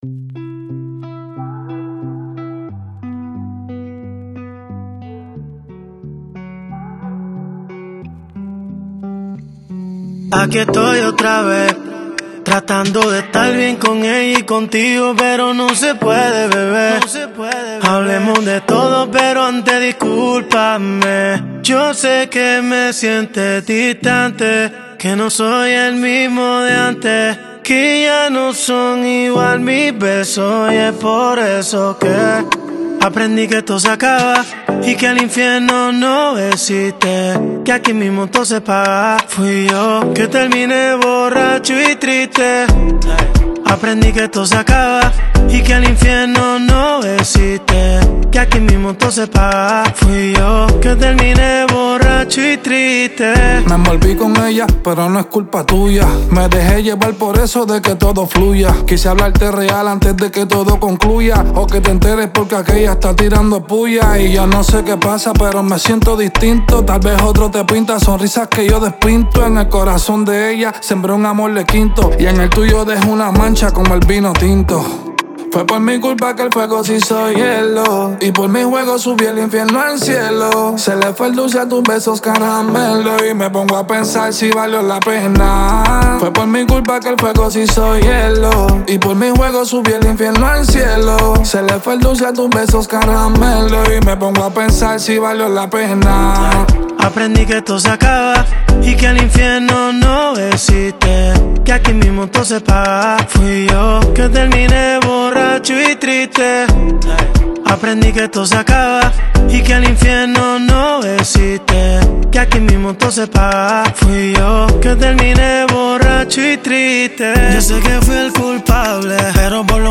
выполненная в жанре реггетон.